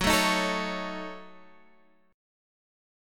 F#m6 chord